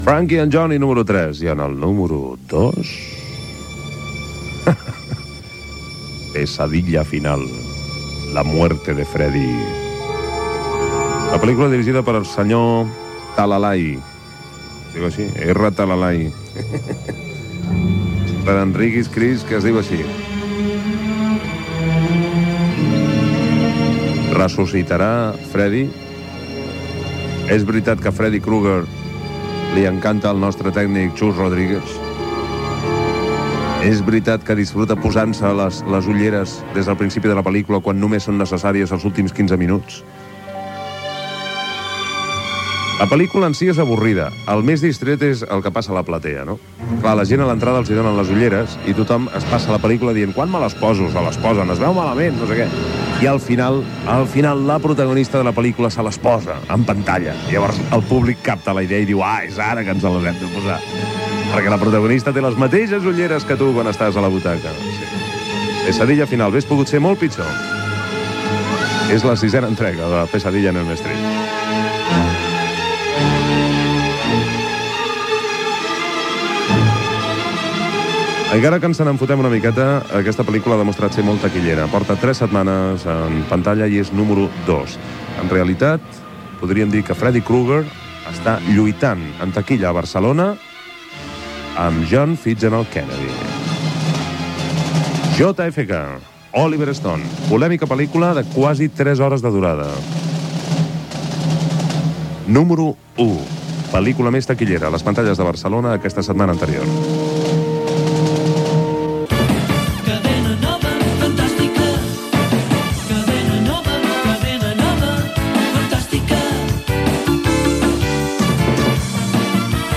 Rànquing de pel·lícules de cinema ("Pesadilla final", "JFK") i indicatiu de l'emissora.
Musical
FM